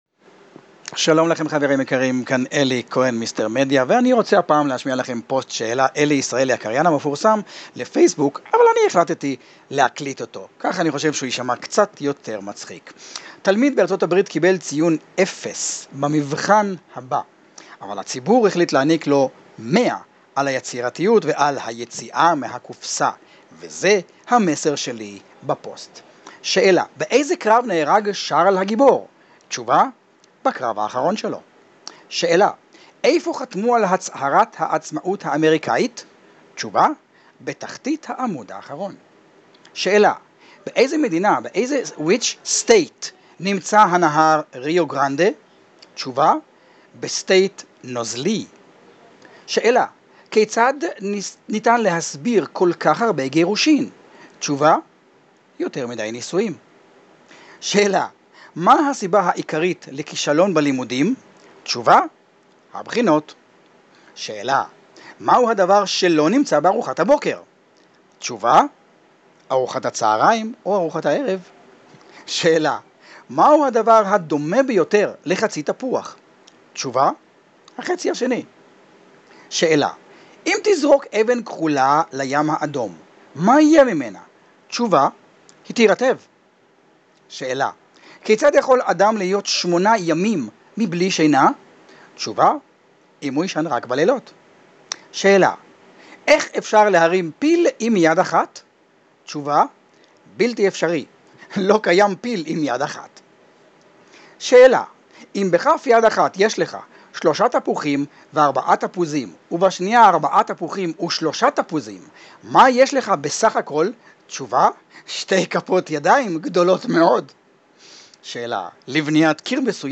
רוב ההקלטות ב-"תחנת הרדיו הפרטית" בוצעו במכשיר הסמארטפון והועלו לכאן ללא כל עריכה, וכך גם אתה יכול להקליט את המסר שלך, ללחוץ עוד קליק או 2, ולשדר את עצמך והמסר שלך - לעולם!